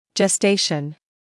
[ʤes’teɪʃn][джэс’тэйшн]созревание плода; беременность